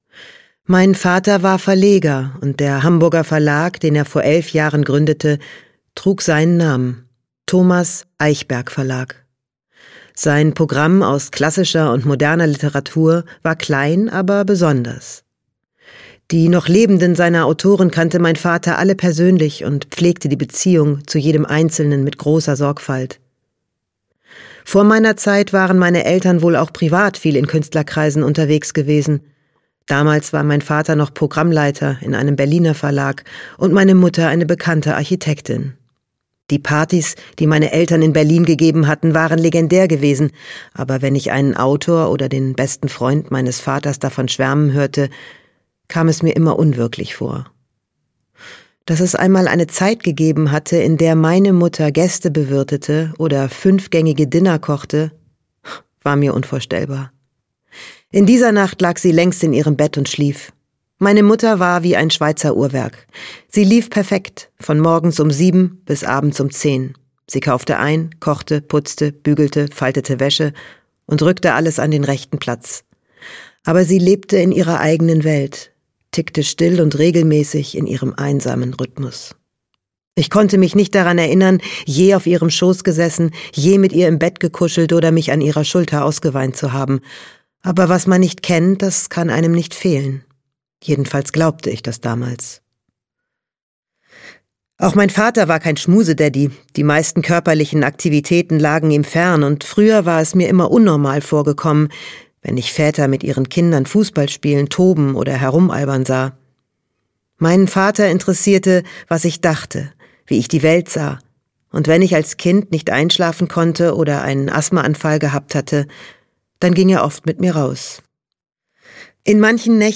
Die längste Nacht - Isabel Abedi - Hörbuch